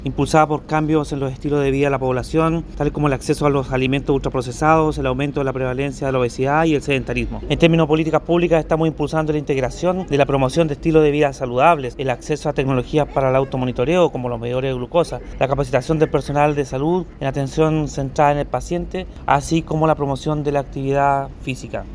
El seremi de Salud de La Araucanía, Andrés Cuyul, dijo que el acceso a los alimentos ultra procesados y el sedentarismo es uno de los motivos del aumento de esta enfermedad, por esto se trabaja en promover un mejor estilo de vida.